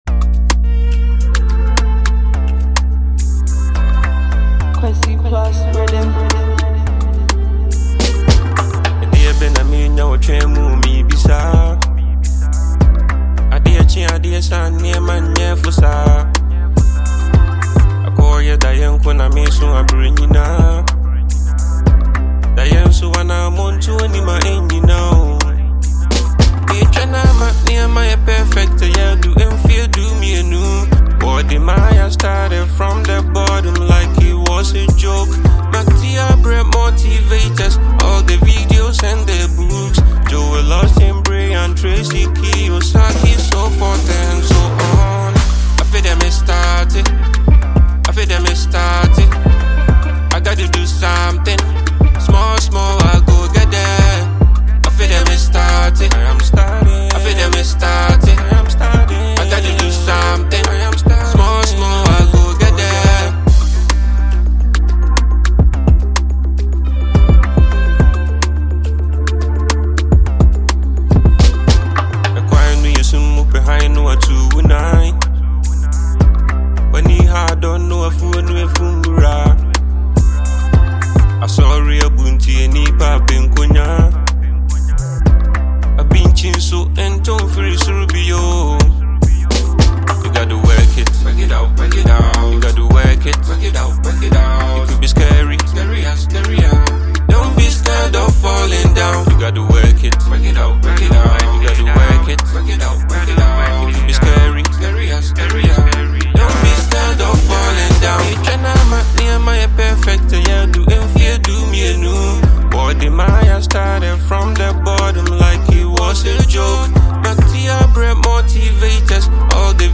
motivational anthem
poetic delivery